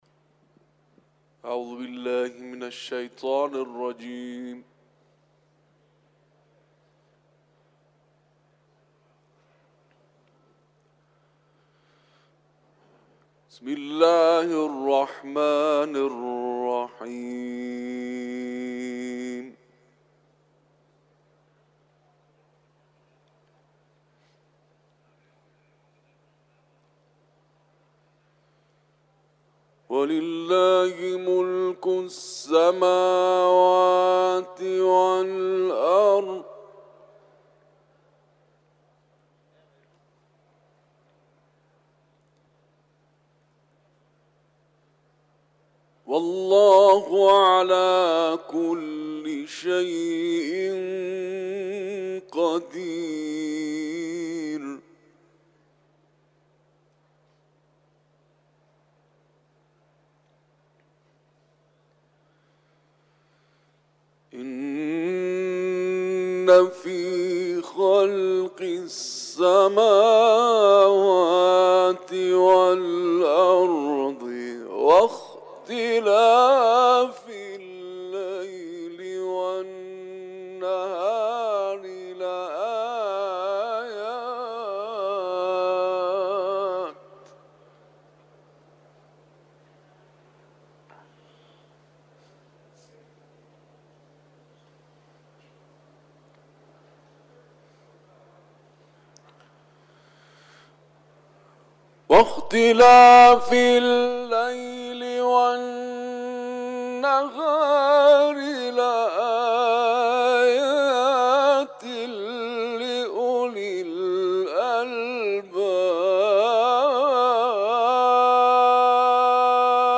تلاوت
حرم مطهر رضوی ، سوره آل عمران